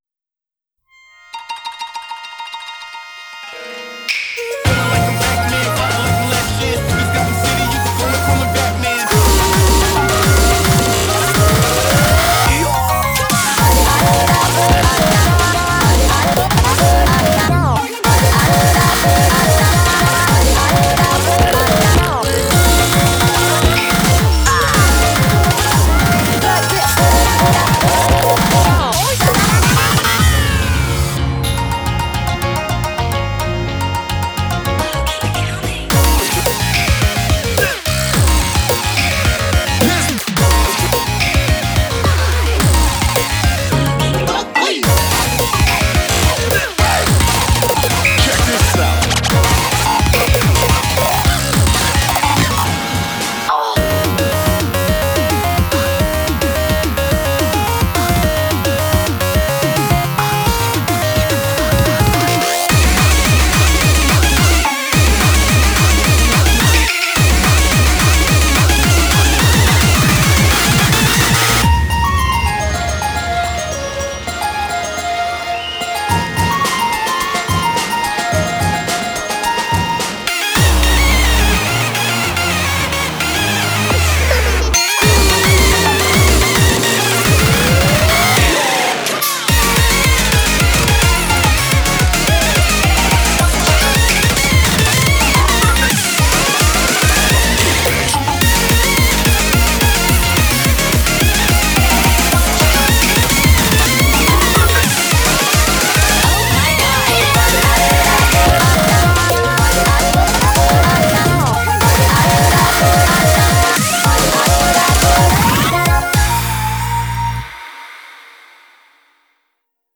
BPM108-215
GENRE: POP BREAKCORE